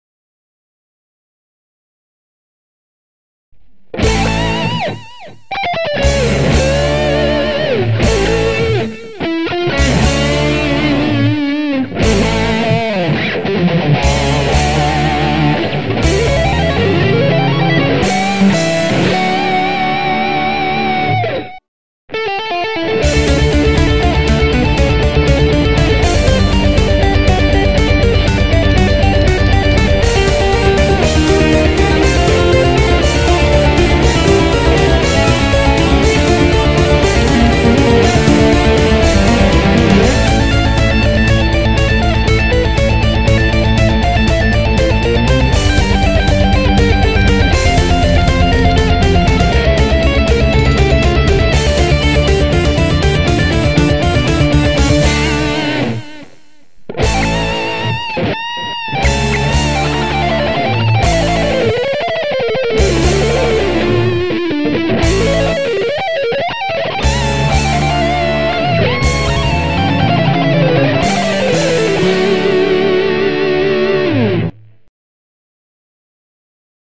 Bändin nimi: Le Ronnié
Ynkkä-tyylinen hevisovitus Bachin tunnetuimmasta urkusävellyksestä.
Hyvin soitettu, hyvällä tatsilla ja hyvällä soundilla.
Hienosti soitettu, tatsi oli hyvä ja muutenkin kuullosti hyvältä.